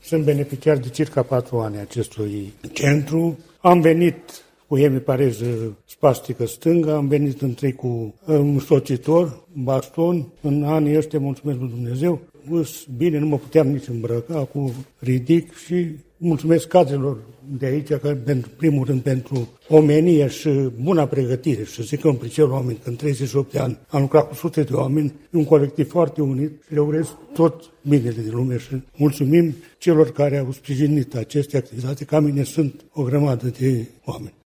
17-aug-ora-10-beneficiar-centru.mp3